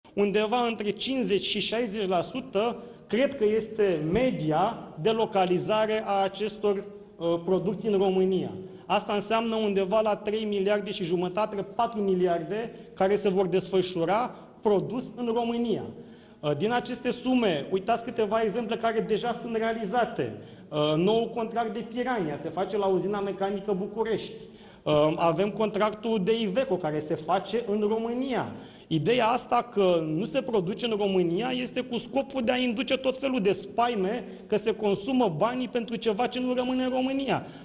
Ministrul Apărării, Radu Miruță: „Ideea că nu se produce în România are scopul de a induce tot felul de temeri”